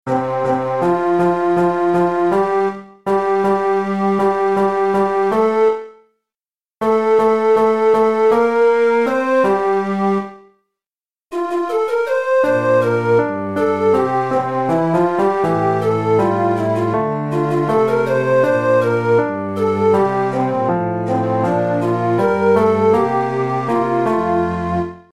Chorproben MIDI-Files 481 midi files